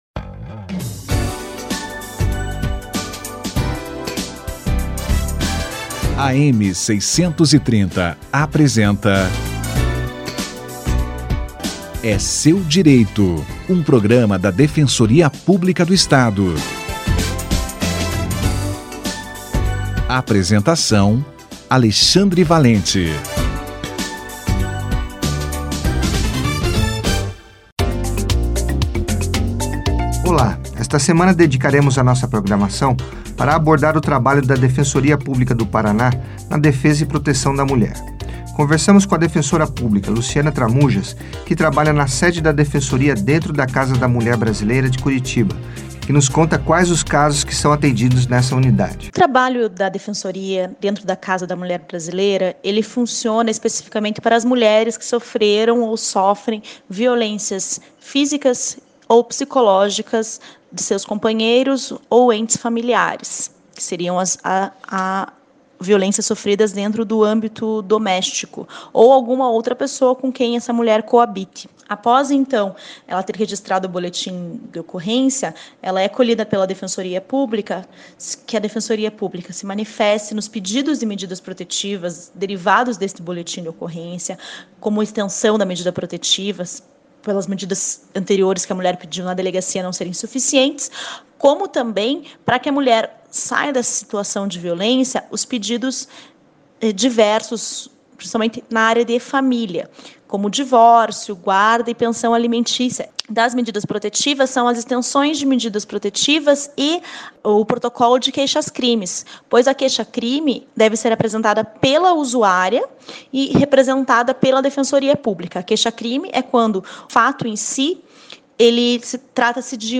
Atendimento na Casa da Mulher - Entrevista